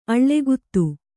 ♪ aḷḷeguttu